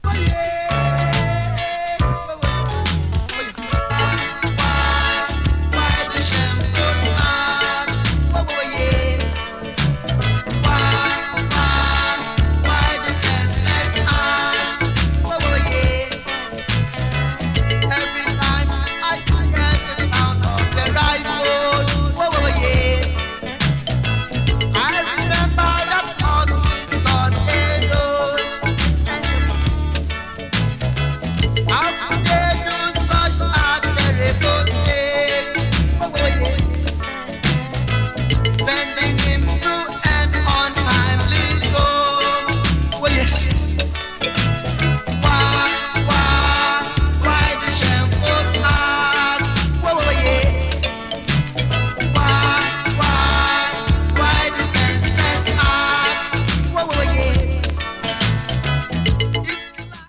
打ち込みのドラムや随所で見られるシンセ・ワークなど
80s AFRO 詳細を表示する